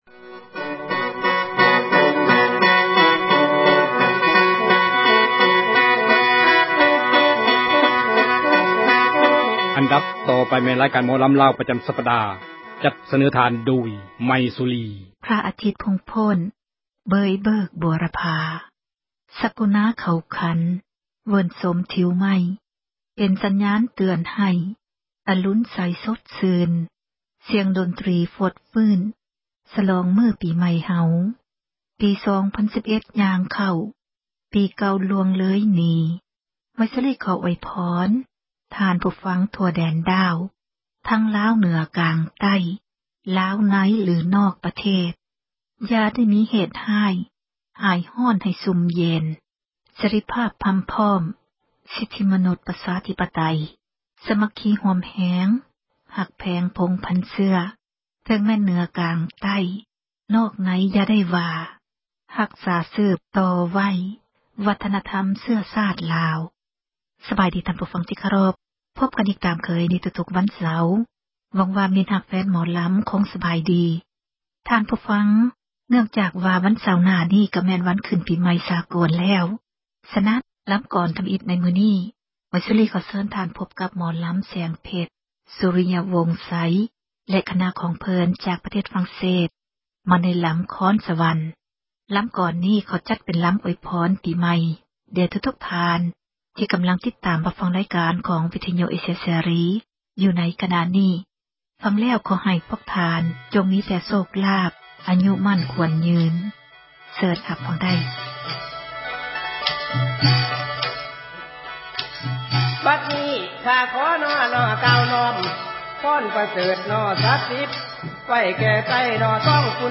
ຣາຍການ ໝໍລໍາ